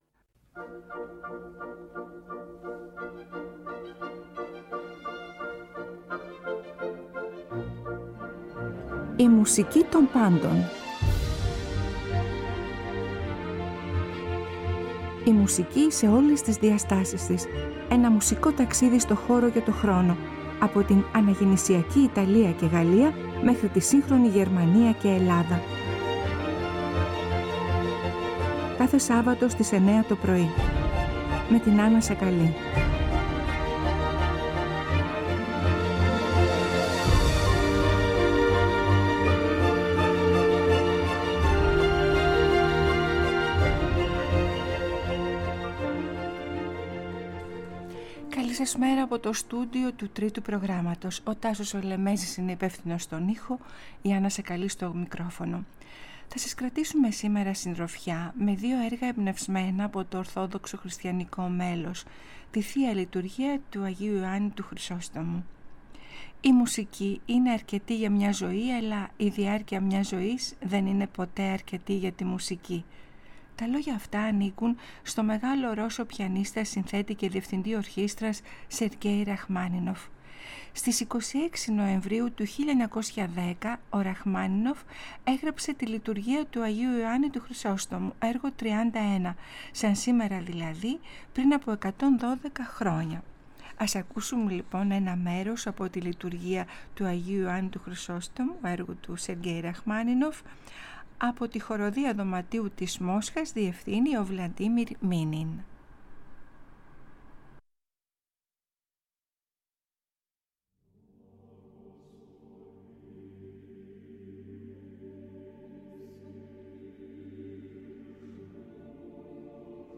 Ερμηνεύει η Χορωδία Δωματίου της Μόσχας (διευθύνει ο Βλαντίμιρ Μίνιν) To 1878 έγραψε ο Πιότρ Ίλιτς Τσαικόφσκι τη Λειτουργία του Αγίου Ιωάννη του Χρυσόστομου.
Ερμηνεύει η Εθνική Ακαδημαϊκή χορωδία της Ουκρανίας «Dumka»